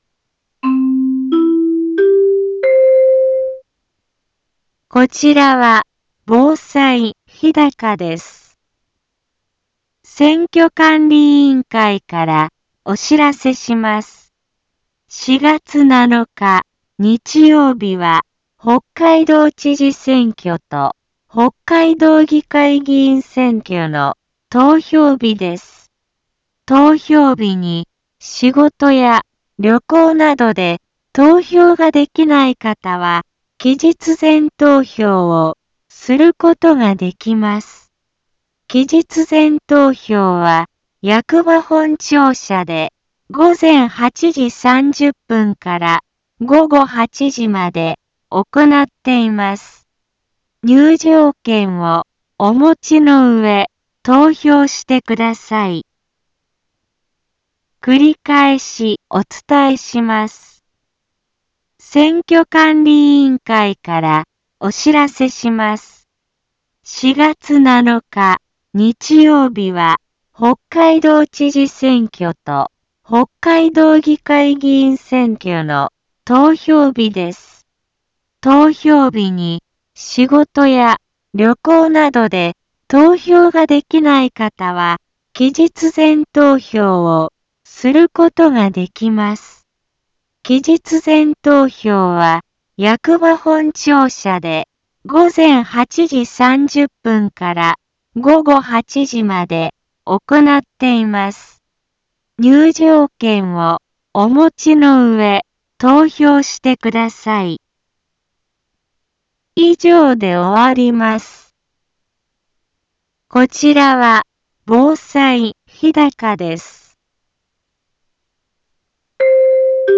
Back Home 一般放送情報 音声放送 再生 一般放送情報 登録日時：2019-03-22 15:04:19 タイトル：北海道知事選挙及び北海道議会議員選挙投票棄権防止の呼びかけ インフォメーション：選挙管理委員会から、お知らせします。